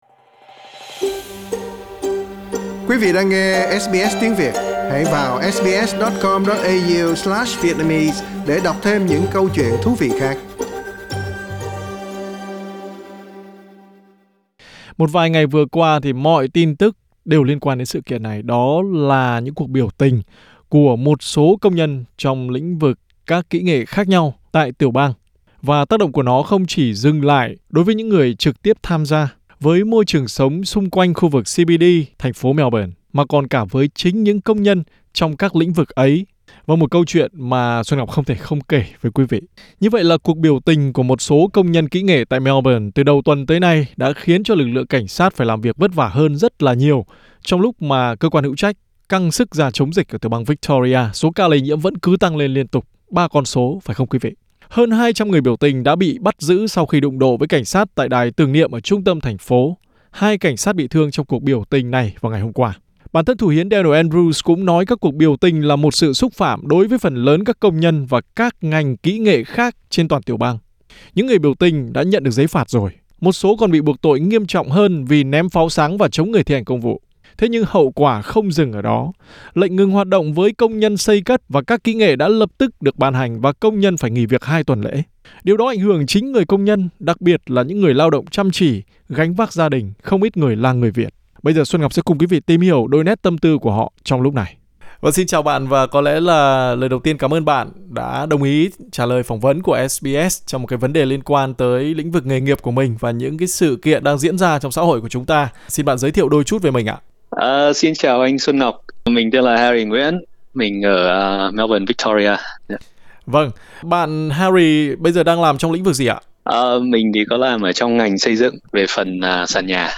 Trả lời phỏng vấn SBS Tiếng Việt